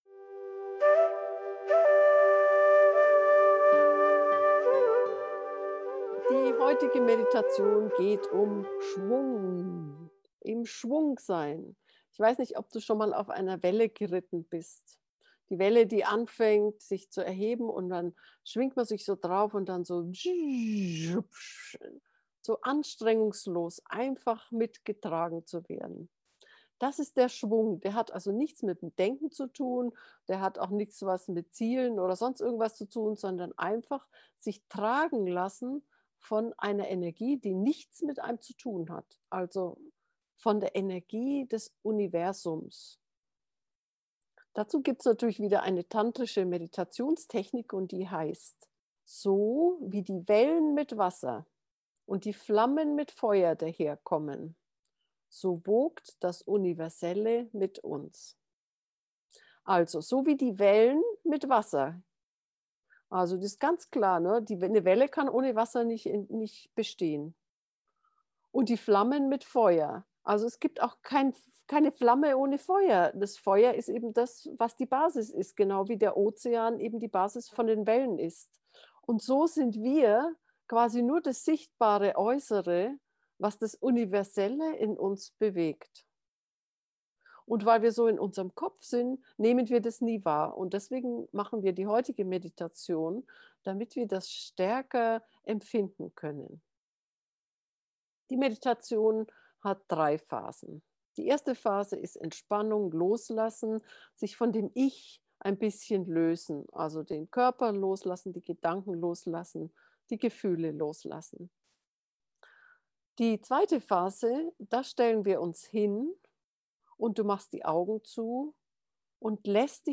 im-schwung-leben-gefuehrte-meditation